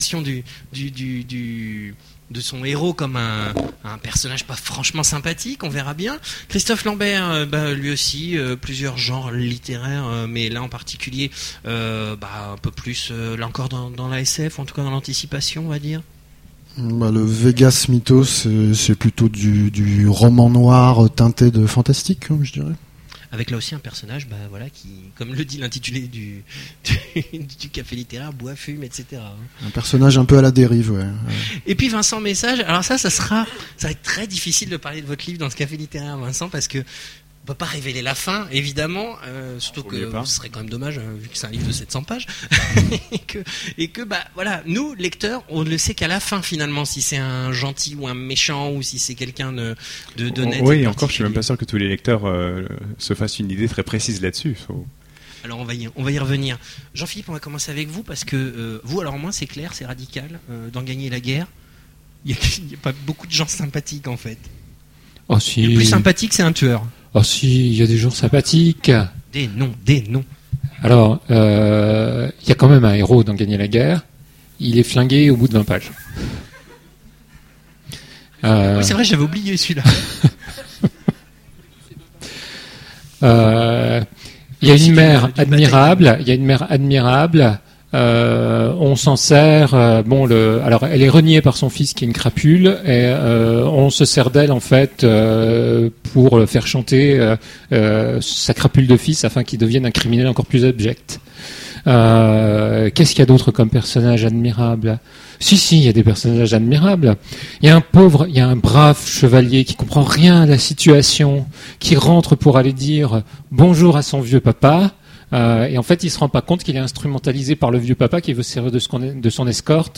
Voici l'enregistrement de la conférence aux Imaginales 2010